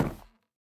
25w18a / assets / minecraft / sounds / block / chiseled_bookshelf / step2.ogg
step2.ogg